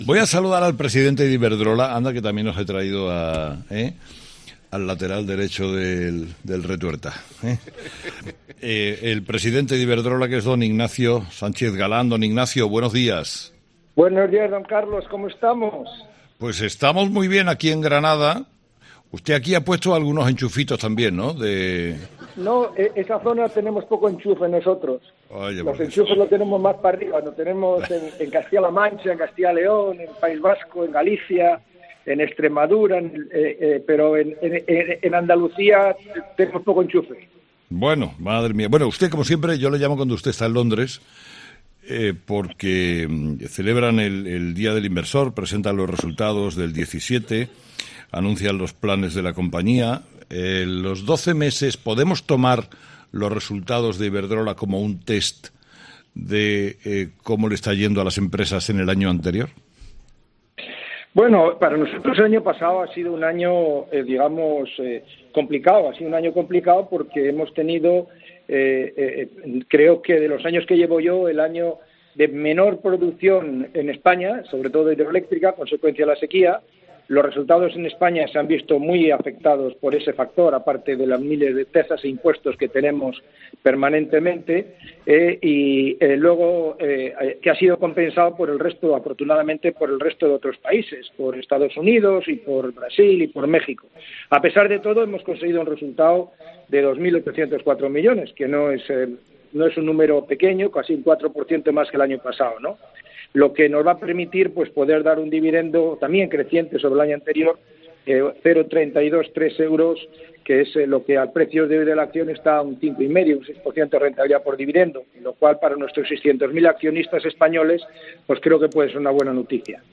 Carlos Herrera entrevista al presidente de Iberdrola, José Ignacio Sánchez Galán